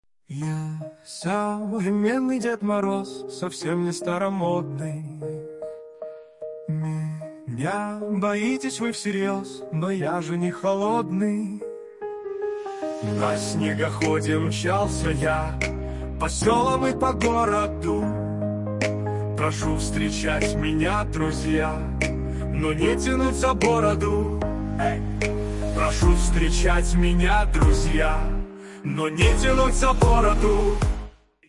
Фрагмент исполнения 3 вариант: